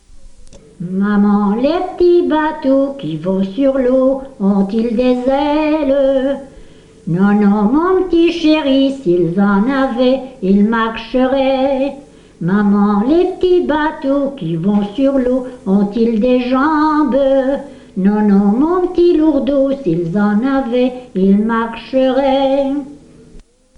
Genre : chant
Type : chanson d'enfants
Lieu d'enregistrement : Lessines
Support : bande magnétique